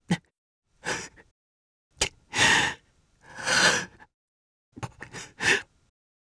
Arch-Vox_Sad_jp.wav